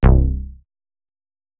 TM88 SYNTH BASS (4).wav